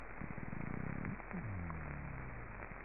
Sounds Made by Epinephelus guttatus
Sound produced yes, active sound production
Type of sound produced courtship associated sounds and territorial displays of males
Sound production organ swim bladder
Sound mechanism vibration by contraction of associated muscles (probably similar as in Epinephelusstriatus)
Behavioural context courtship displays between males and females and territorial displays by males recorded in situ during the spawning aggregation in western Puerto Rico